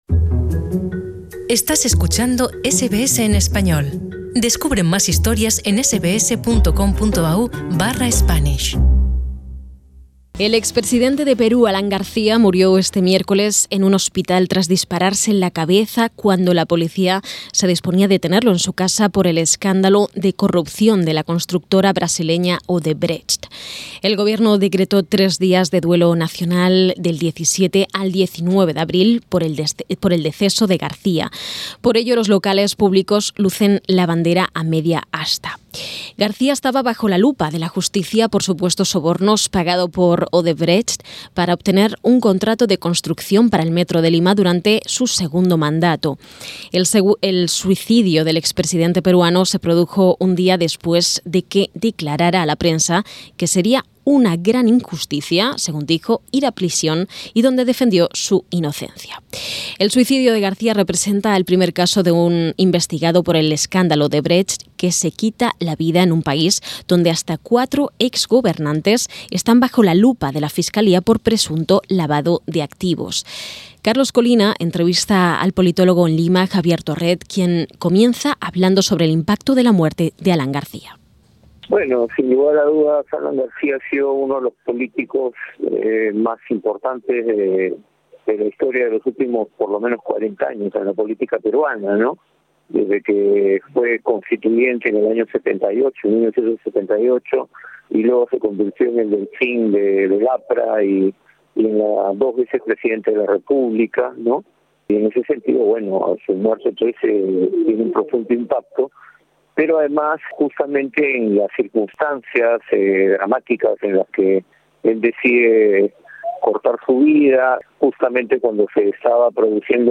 Entrevista con el politólogo en Lima